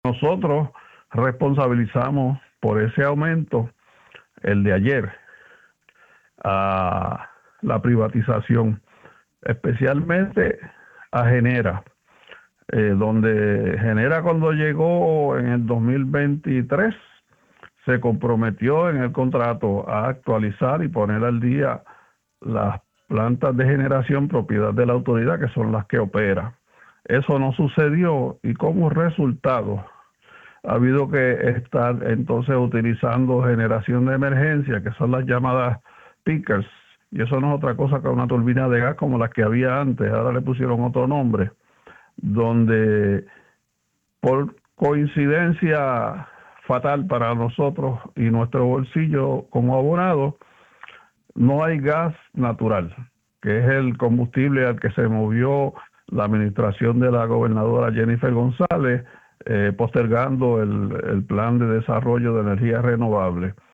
en entrevista con Radio Isla.